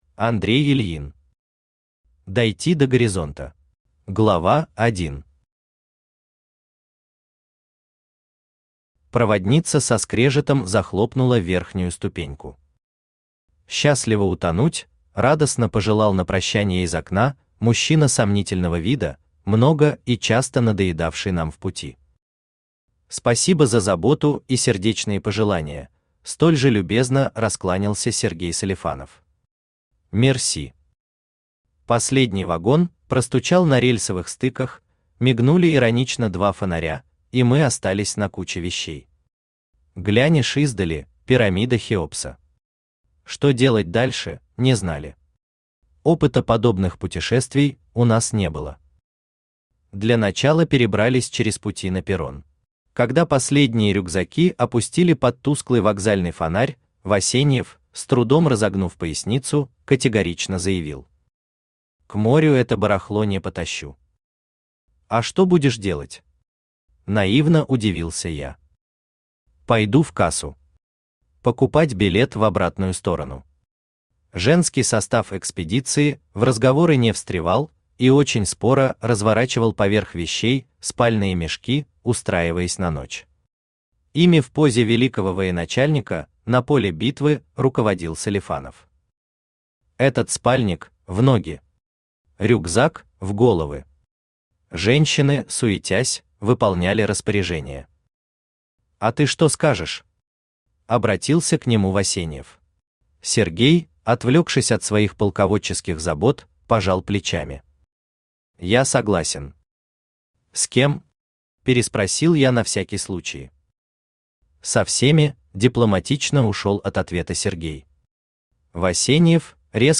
Aудиокнига Дойти до горизонта Автор Андрей Александрович Ильин Читает аудиокнигу Авточтец ЛитРес.